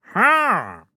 Minecraft Version Minecraft Version snapshot Latest Release | Latest Snapshot snapshot / assets / minecraft / sounds / mob / wandering_trader / yes3.ogg Compare With Compare With Latest Release | Latest Snapshot